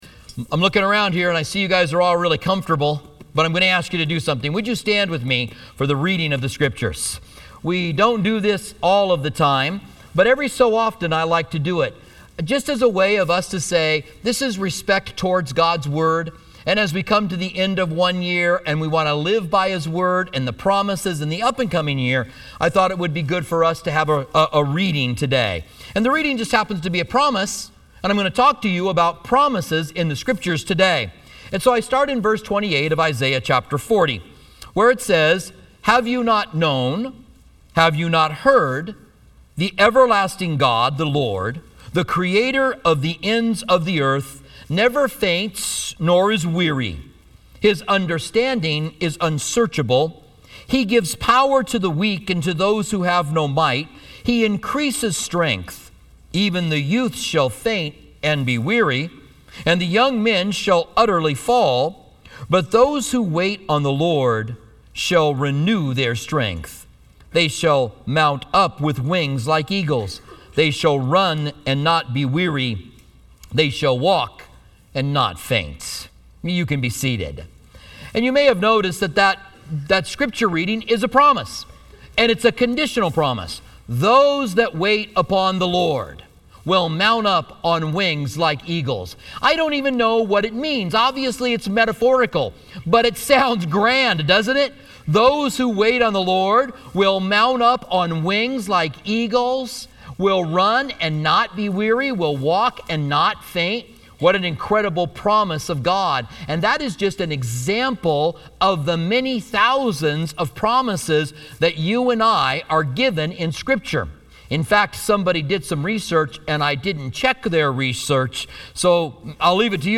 Holiday Message